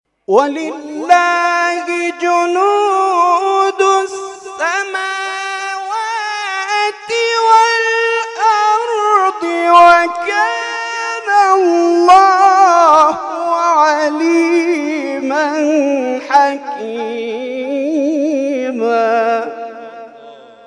گروه جلسات و محافل: محفل انس با قرآن کریم این هفته آستان عبدالعظیم الحسنی(ع) با تلاوت دو قاری ممتاز کشورمان و ارائه محفوظات حافظ کل قرآن برگزار شد.
این محفل انس با قرآن هر هفته جمعه‌ها با حضور زائران و مجاوران این آستان مقدس برگزار می‌شود.